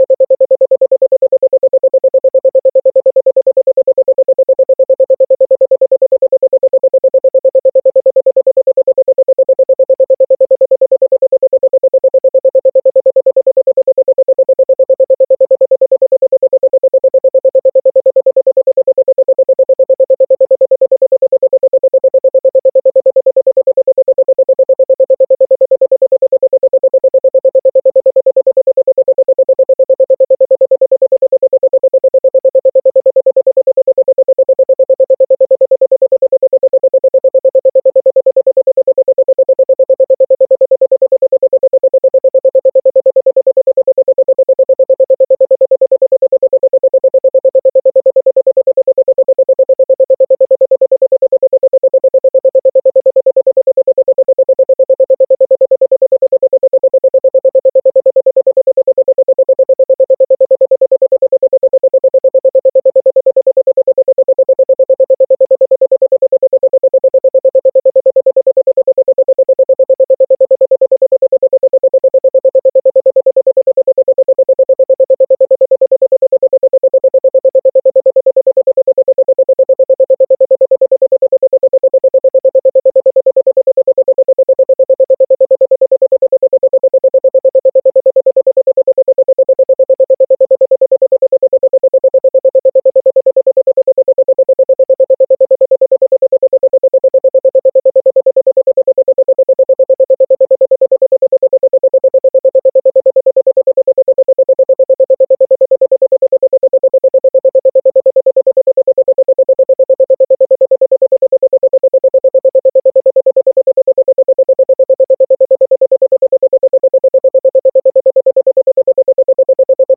Isochronic Tones